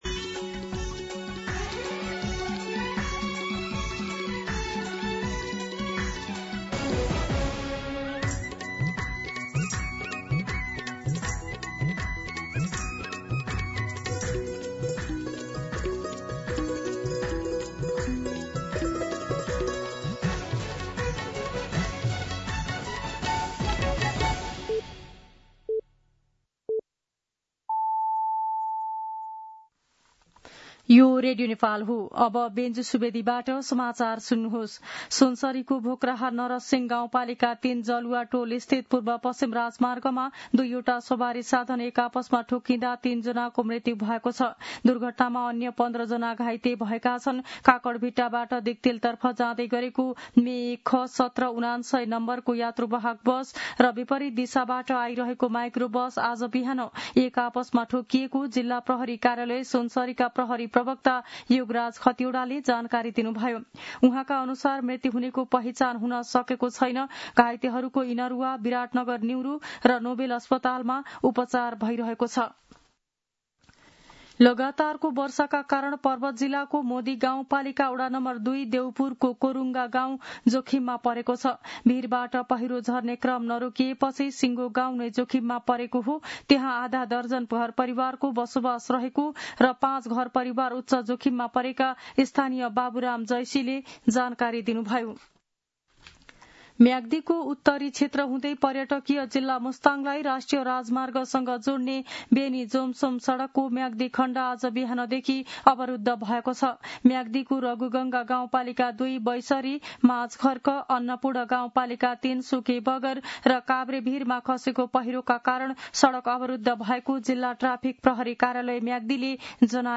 मध्यान्ह १२ बजेको नेपाली समाचार : २६ साउन , २०८२
12-pm-Nepali-News-4.mp3